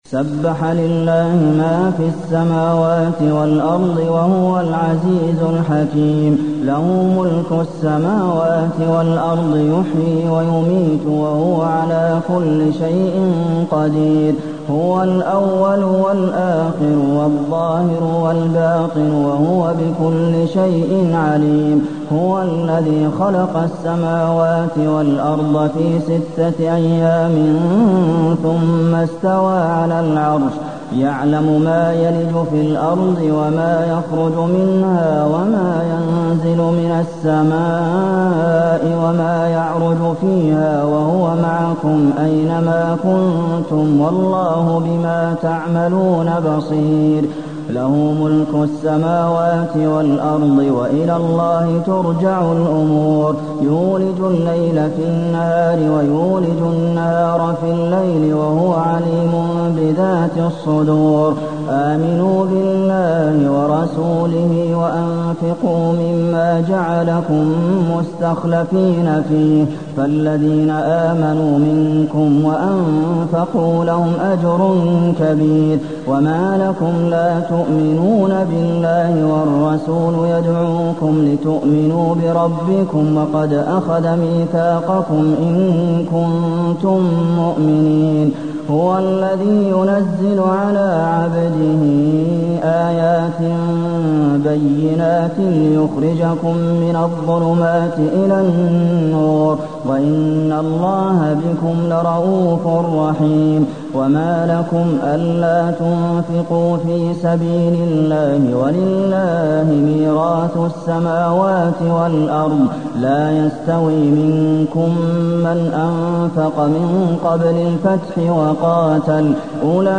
المكان: المسجد النبوي الحديد